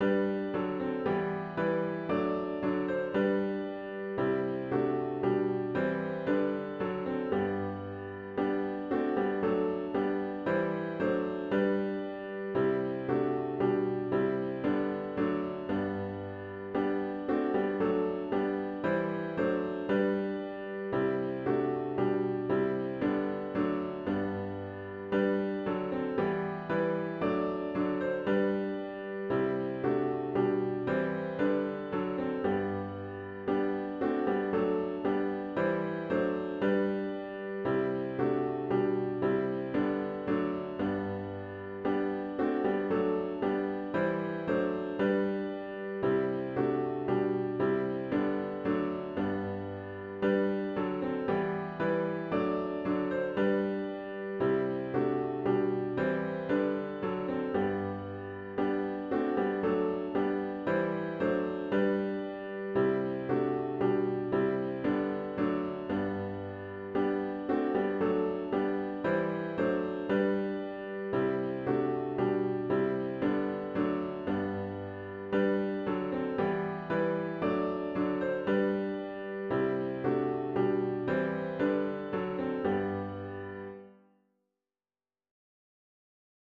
OPENING HYMN “Christ, Whose Glory Fills the Skies” GtG 662 (Tune 150)